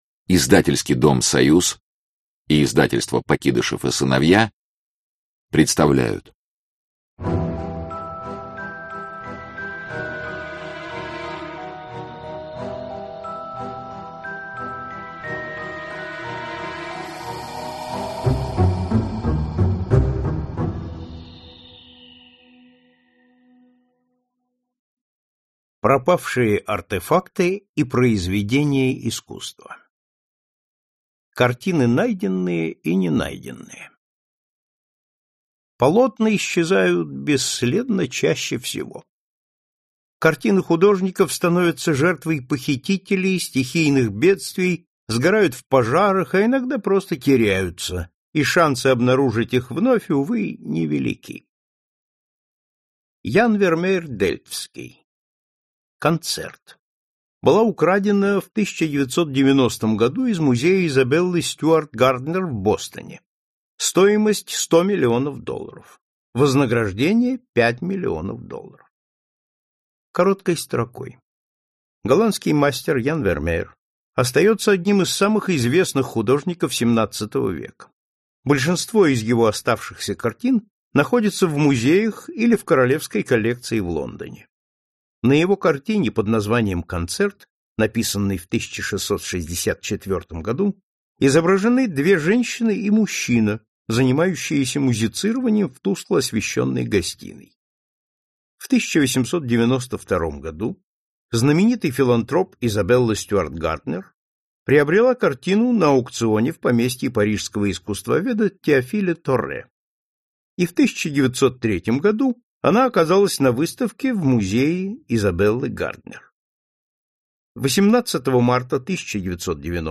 Аудиокнига Исчезнувшие артефакты и клады | Библиотека аудиокниг